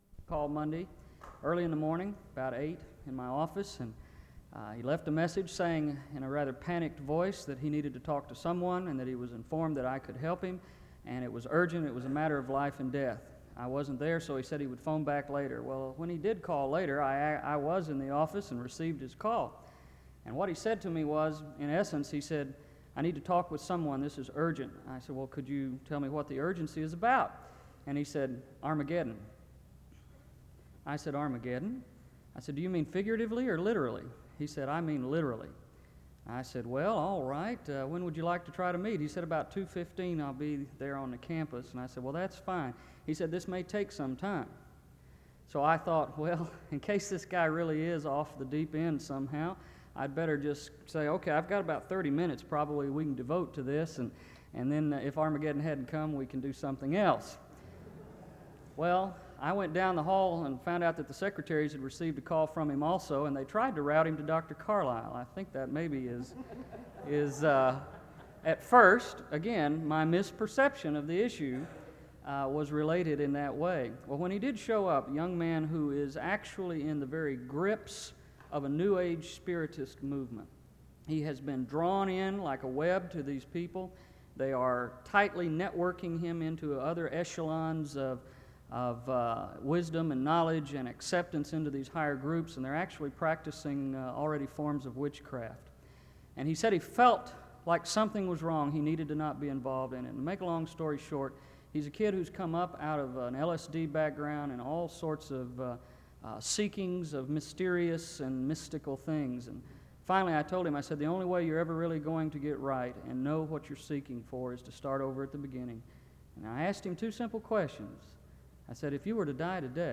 File Set | SEBTS_Chapel_Jim_Jacumin_1994-08-31.wav | ID: bc9a9abb-0274-45fe-927a-472d91d83762 | Hyrax